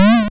Bumper.wav